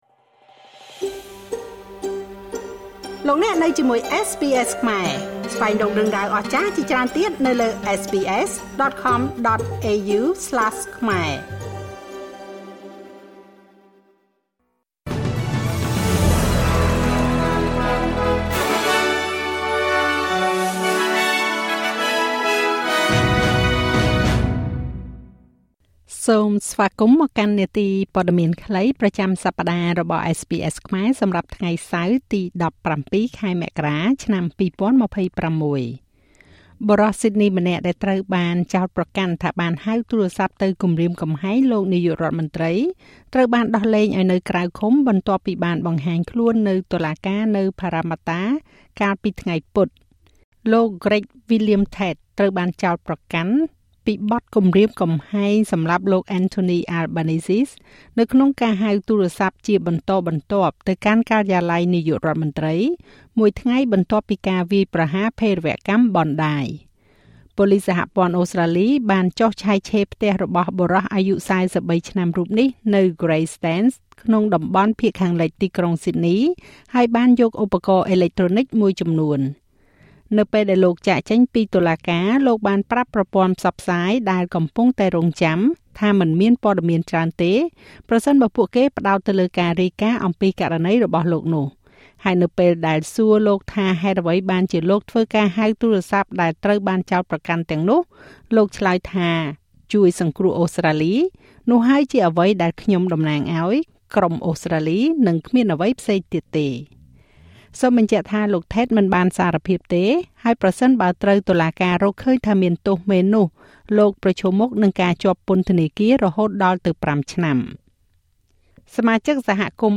នាទីព័ត៌មានខ្លីប្រចាំសប្តាហ៍របស់SBSខ្មែរ សម្រាប់ថ្ងៃសៅរ៍ ទី១៧ ខែមករា ឆ្នាំ២០២៦